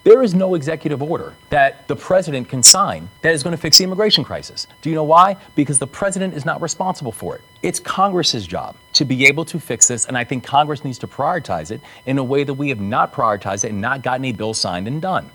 Maryland Governor Wes Moore addressed a number of issues during a town hall interview with WBFF-TV.  On the topic of immigration, Moore says it is a subject he takes very seriously, being the son of an immigrant woman.